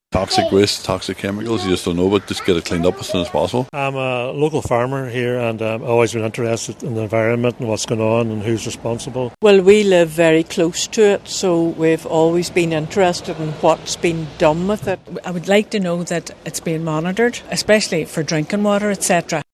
Local voice opinion on Mobouy illegal dump in Derry
The first of two events has been held to gather the public’s view on plans to clear the site: